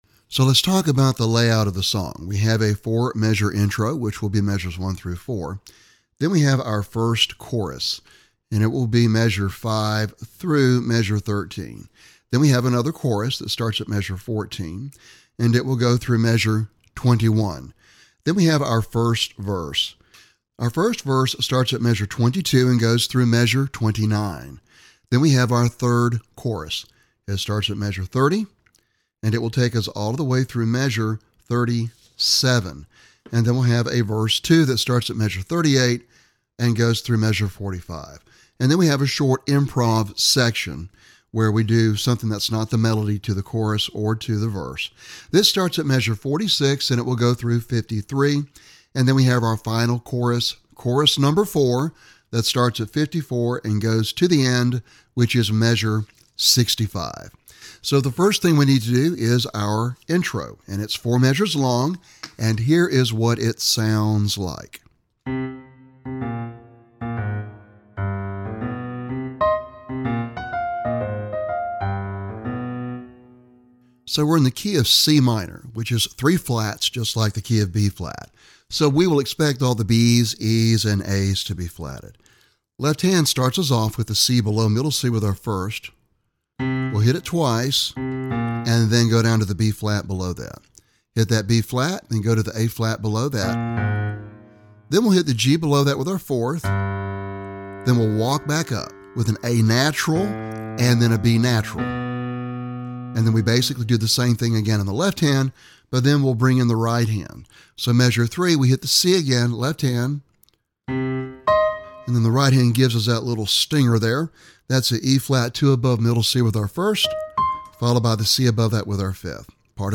Piano Solo - Early Advanced
This old spiritual makes a nice jazzy arrangement!